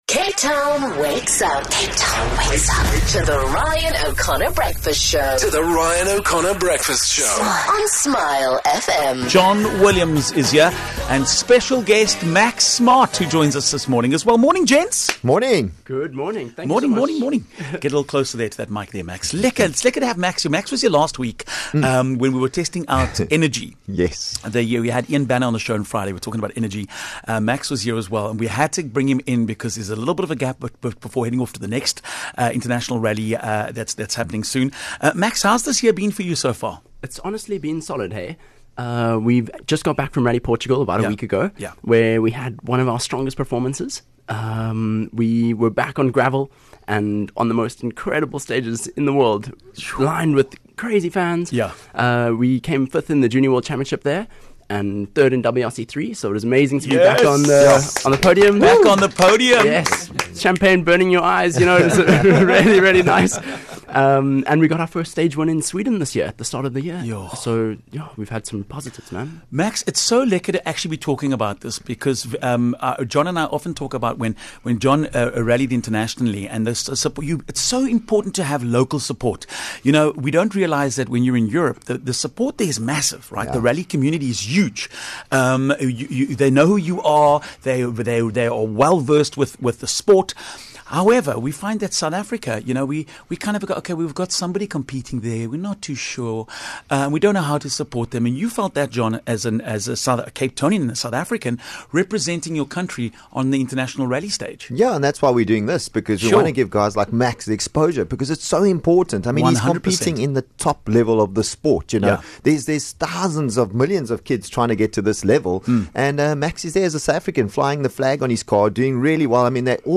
a chat on The Fastlane